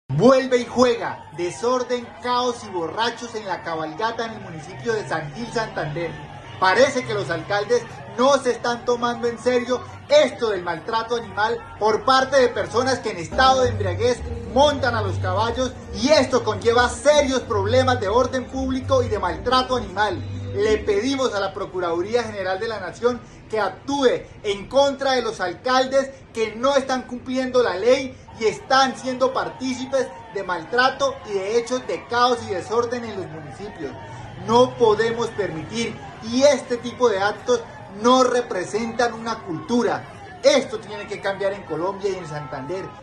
Danovis Lozano, diputado de Santander